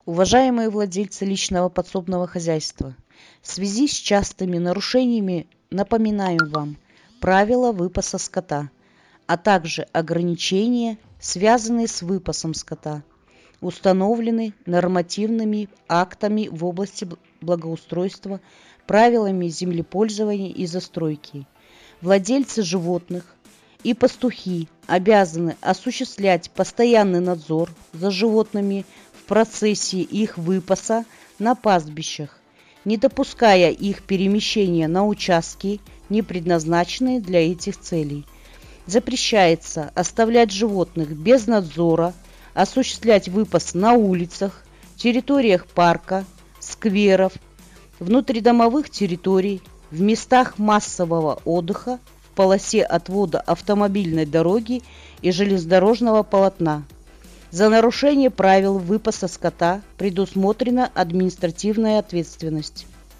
Комментирует глава СП “Билитуйское” Жанна Ковалёва.
Глава-с.-Билитуй-Ковалёва-о-выпасе-скота.mp3